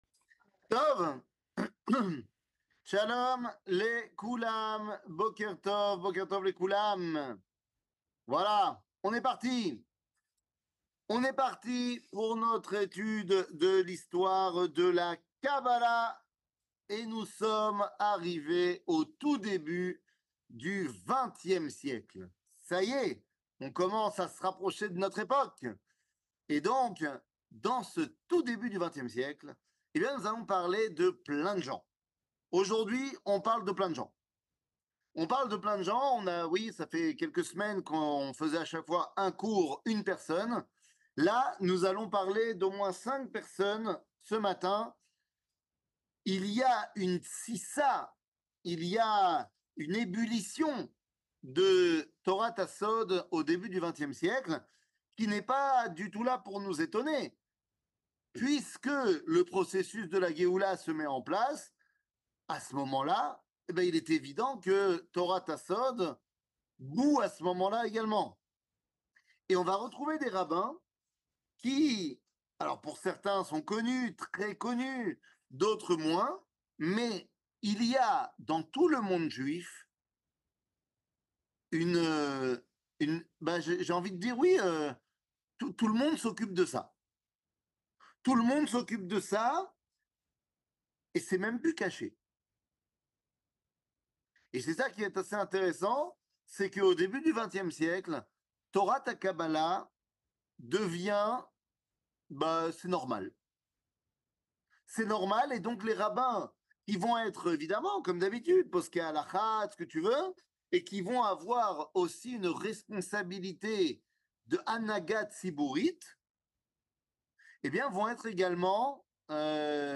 שיעורים, הרצאות, וידאו
שיעור